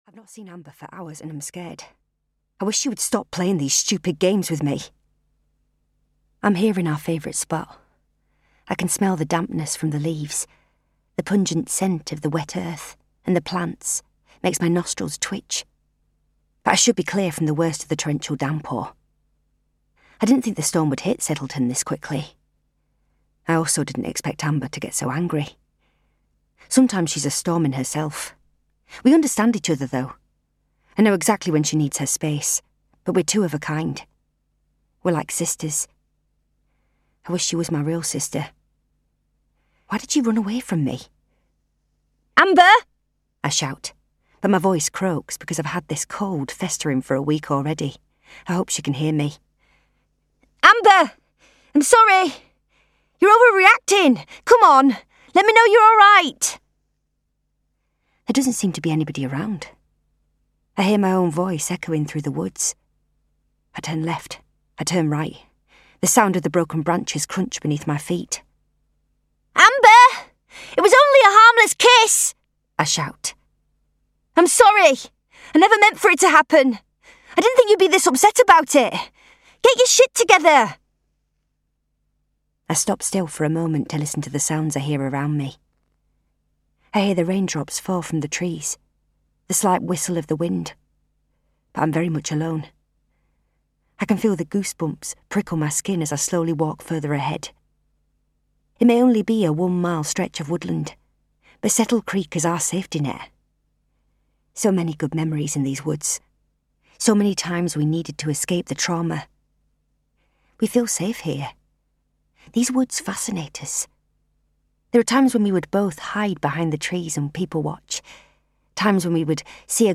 I Let Her Go (EN) audiokniha
Ukázka z knihy